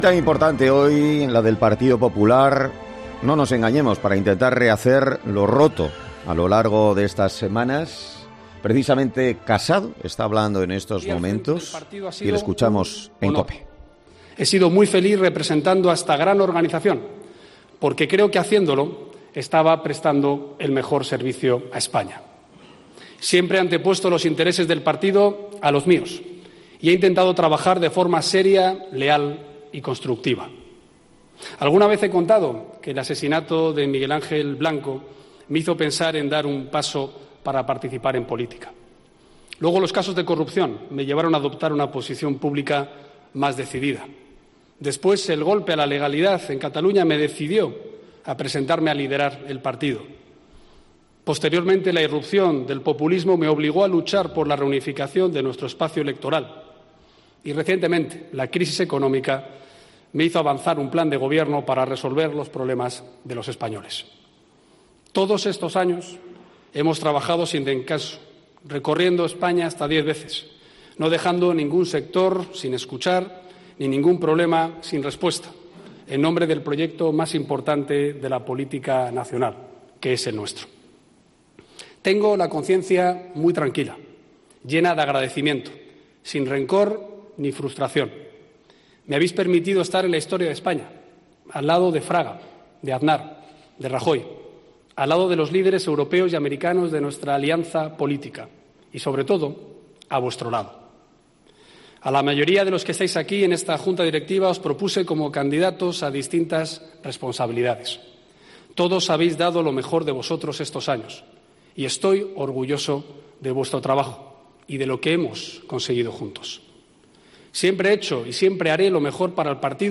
Pablo Casado se despide del PP entre aplausos: "Lamento todo lo que haya hecho mal"
Pablo Casado se ha despedido en la Junta Directiva Nacional del Partido Popular con un discurso en el que ha defendido su etapa al frente del partido